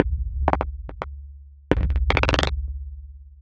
tx_perc_140_minchaotic2.wav